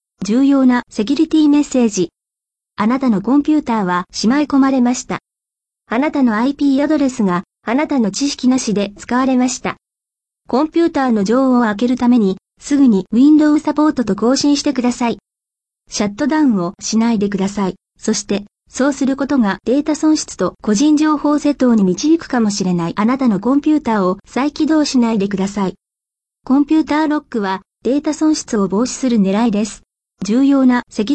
It also has the same warning voice message as the previous versions in the post titled: New feature on the fake MS tech support scam.